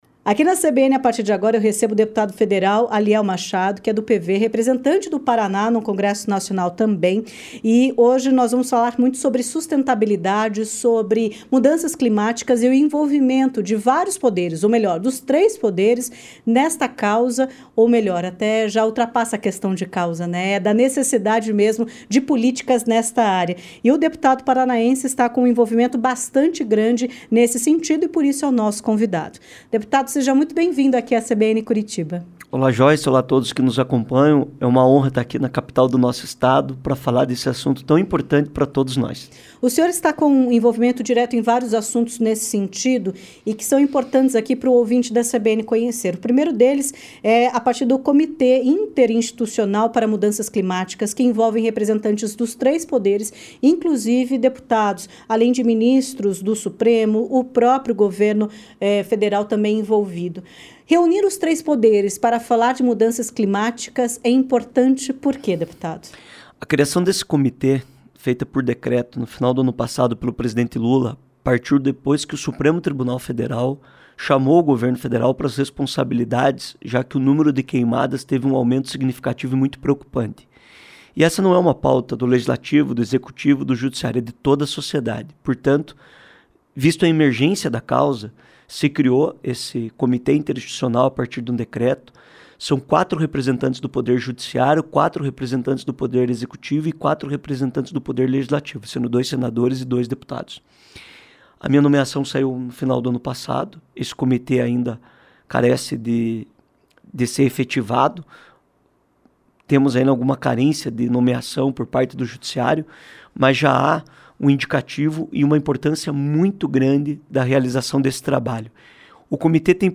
Em entrevista à CBN Curitiba, ele destacou o trabalho do comitê e os efeitos da lei que regulamenta o mercado de carbono no país.
ENTREVISTA-ALIEL-MACHADO.mp3